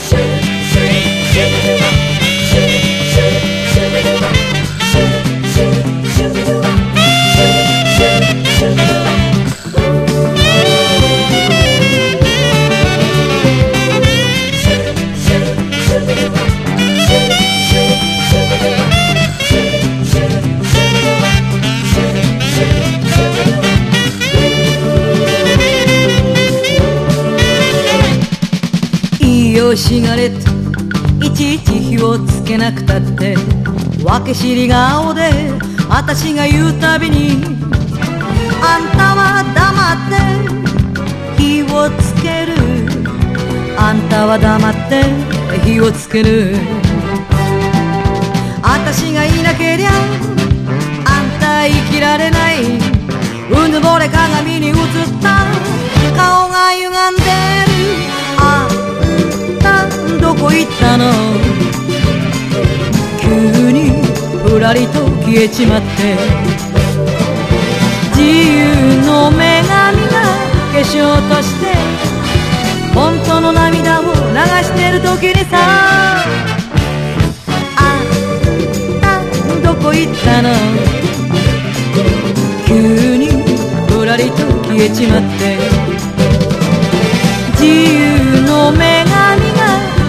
EASY LISTENING
グルーヴィーなビートと華麗なアレンジの和製インスト・カヴァー集！